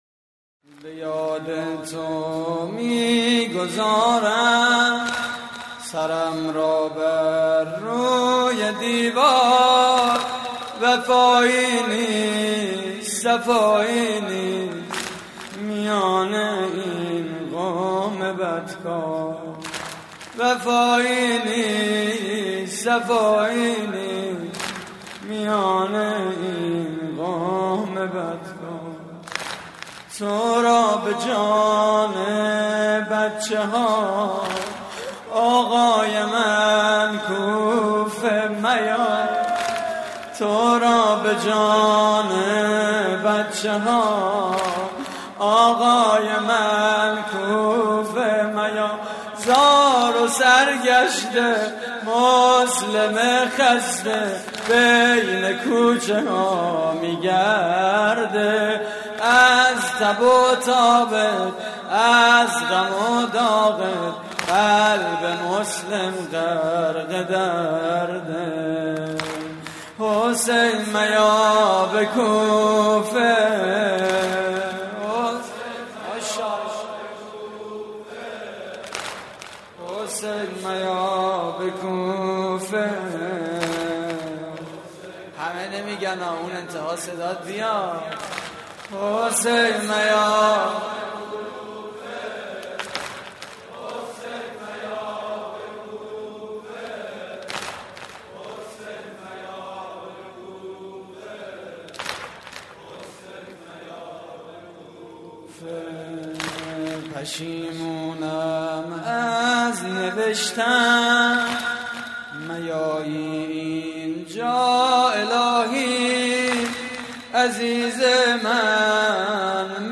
مراسم عزاداری شب اول ماه محرم / هیئت الزهرا (س) – دانشگاه صنعتی شریف؛ 25 آبان 1391